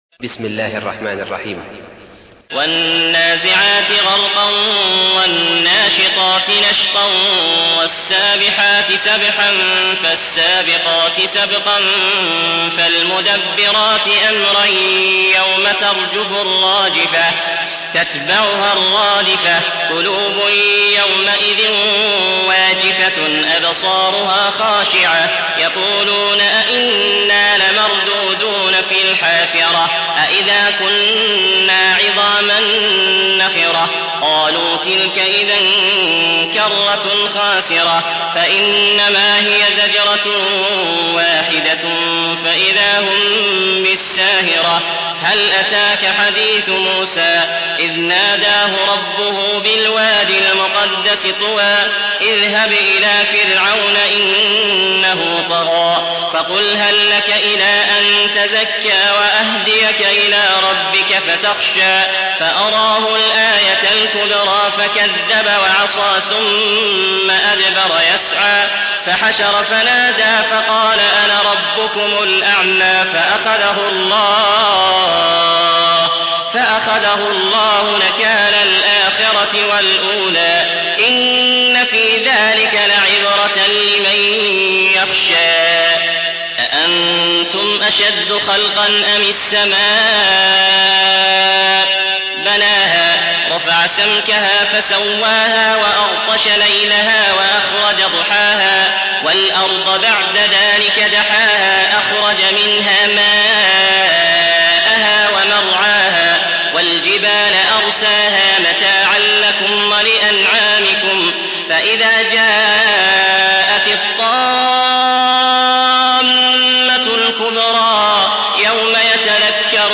Surah Sequence تتابع السورة Download Surah حمّل السورة Reciting Mutarjamah Translation Audio for 79. Surah An-Nazi'�t سورة النازعات N.B *Surah Includes Al-Basmalah Reciters Sequents تتابع التلاوات Reciters Repeats تكرار التلاوات